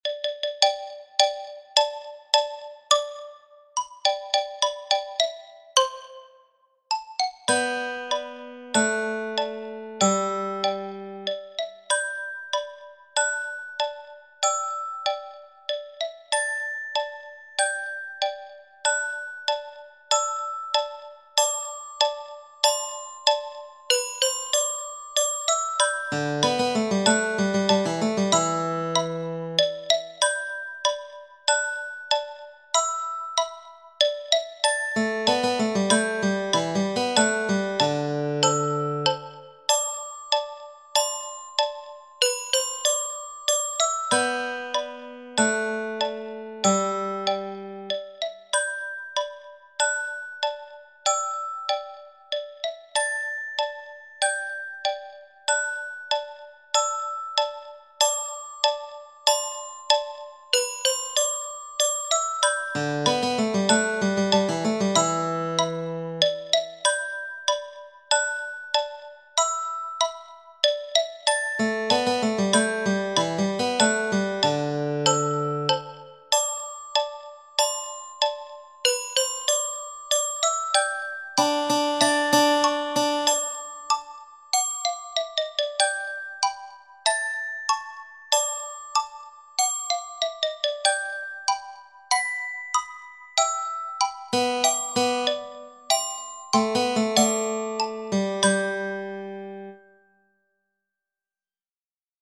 silent bars